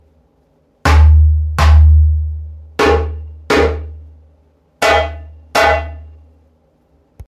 ヘッドは高低バランスのよい32cm。このサイズならではのキレのあるジャンベ音が楽しめます。広いレンジでパーフェクトなジャンベサウンドが楽しめます。
この楽器のサンプル音